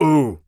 Male_Grunt_Hit_Neutral_04.wav